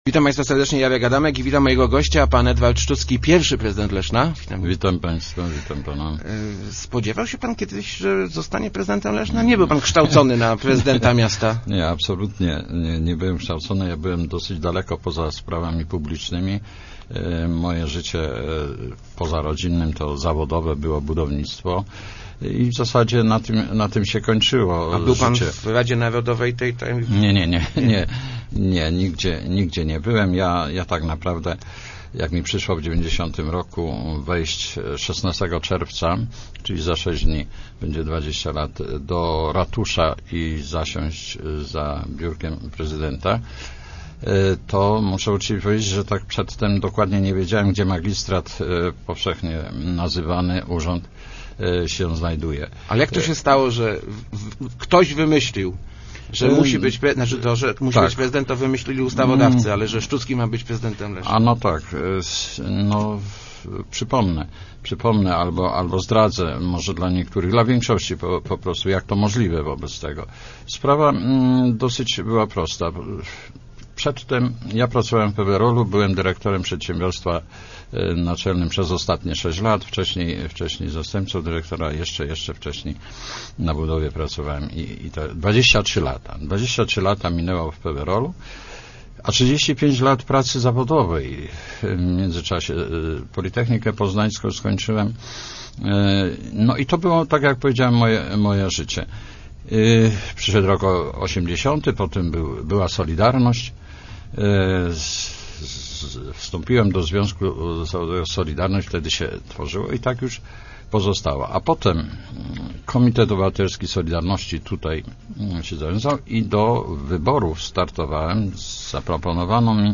Pierwszym wyzwaniem, przed którym stan�� samorz�d Leszna by�a poprawa zaopatrzenia w wod� – mówi� w Rozmowach Elki Edward Szczucki, pierwszy prezydent miasta. Za tydzie� minie 20 rocznica jego powo�ania przez Rad� Miasta, zdominowan� wtedy przez solidarno�ciowy Komitet Obywatelski.